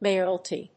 may・or・al・ty /méɪərəlti, mé(ə)r‐méər‐/
• / méɪərəlti(米国英語)